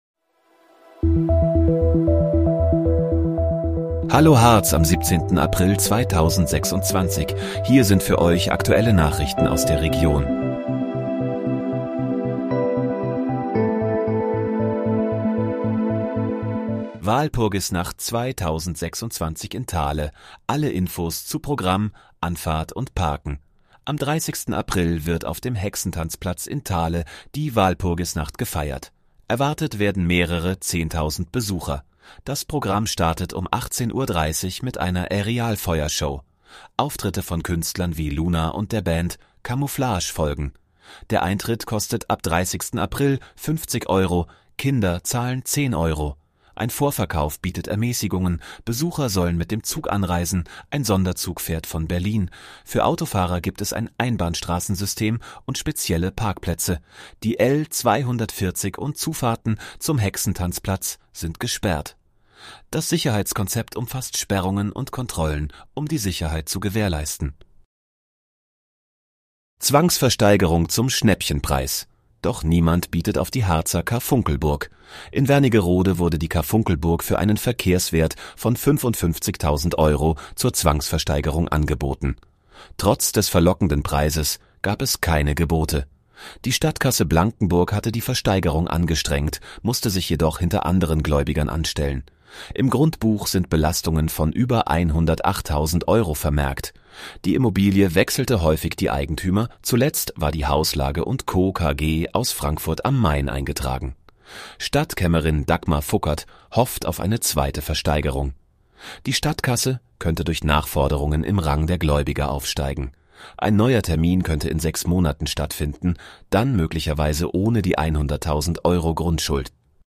Hallo, Harz: Aktuelle Nachrichten vom 17.04.2026, erstellt mit KI-Unterstützung